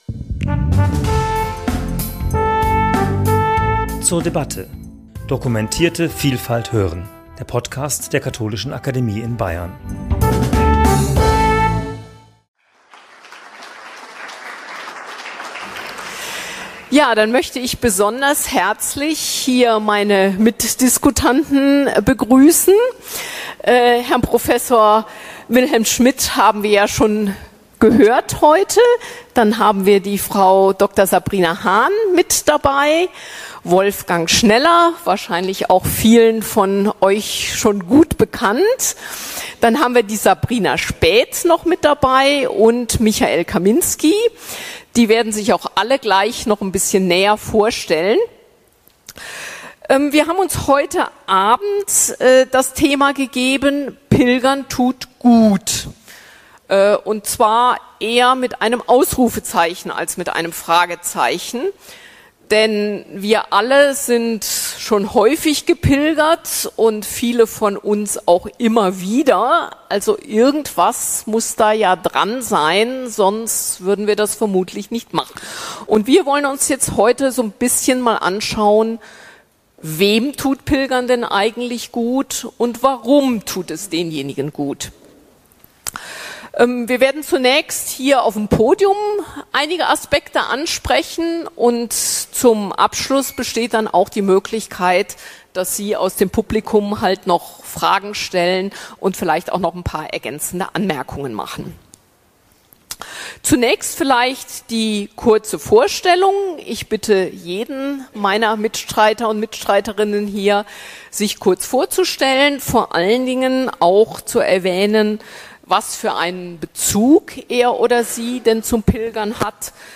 Podiumsdiskussion zum Thema 'Pilgern tut gut' ~ zur debatte Podcast
Beschreibung vor 1 Jahr Der Podcast dokumentiert die Podiumsdiskussion "Pilgern tut gut" und gibt einige Statements von Pilgerinnen und Pilgern wieder.